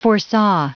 Prononciation du mot foresaw en anglais (fichier audio)
Prononciation du mot : foresaw